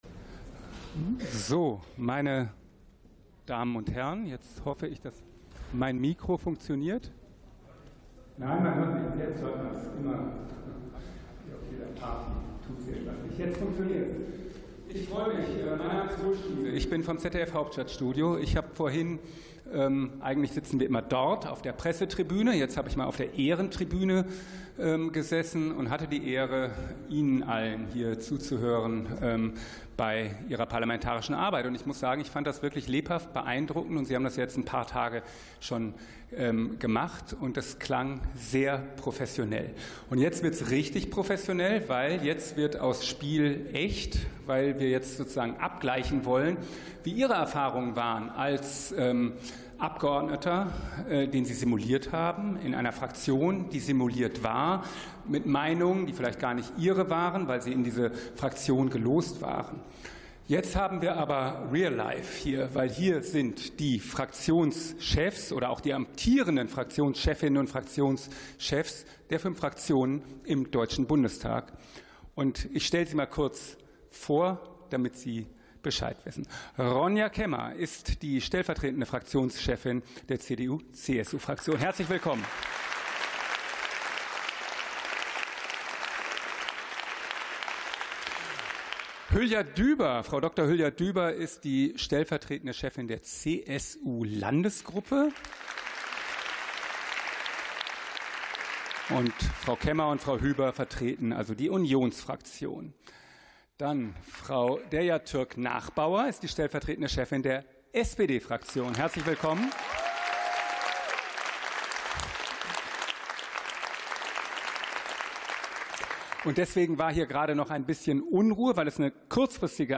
Jugend und Parlament 2025: Podiumsdiskussion ~ Sonderveranstaltungen - Audio Podcasts Podcast